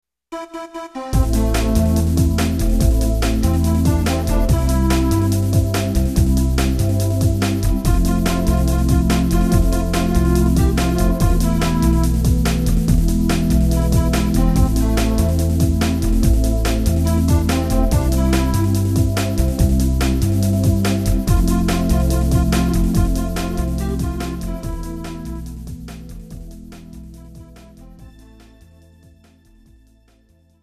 Žánr: Pop